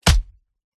Звуки пощечины
Простой чистый удар по телу без свиста рук 5